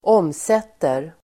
Uttal: [²'åm:set:er]